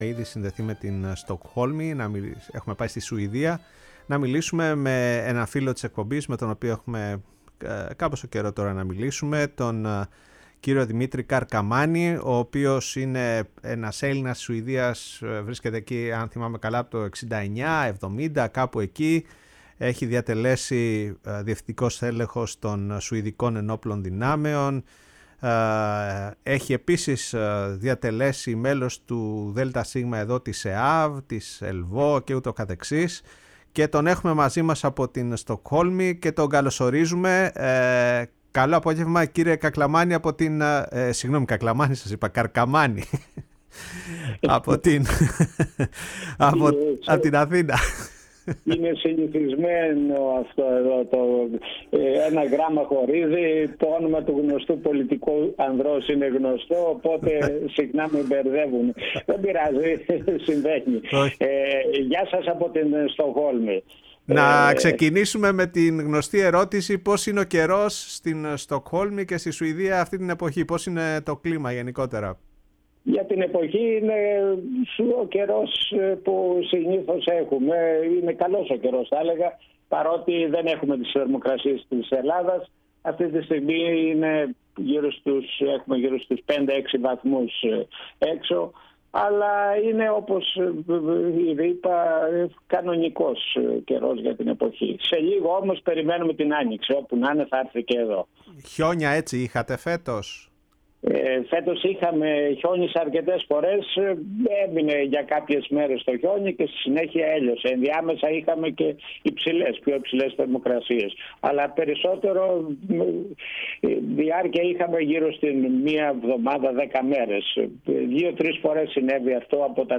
Η ΦΩΝΗ ΤΗΣ ΕΛΛΑΔΑΣ Η Παγκοσμια Φωνη μας ΟΜΟΓΕΝΕΙΑ ΣΥΝΕΝΤΕΥΞΕΙΣ Συνεντεύξεις ΝΑΤΟ ΟΥΓΓΑΡΙΑ Σουηδια ΤΟΥΡΚΙΑ